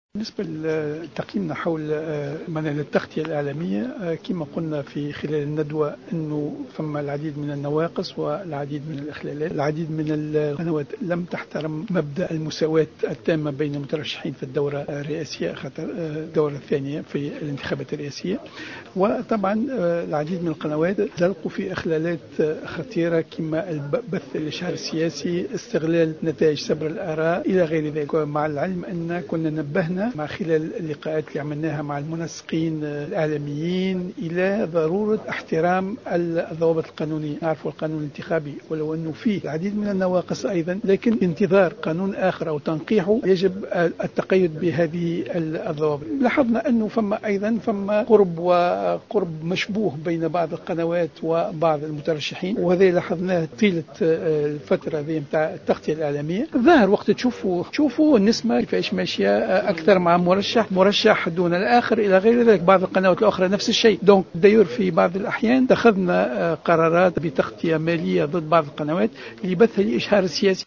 Dans une déclaration accordée à Jawhara FM, le président de la Haute Autorité Indépendante de la Communication Audiovisuelle (HAICA), Nouri Lajmi, a révélé que des médias n’ont pas respecté le droit d’égalité dans l’accès aux médias et la règle de la couverture équitable des candidats à la présidentielle lors de la campagne électorale du deuxième tour.